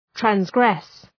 Προφορά
{træns’gres} (Ρήμα) ● υπερβαίνω ● καταπατώ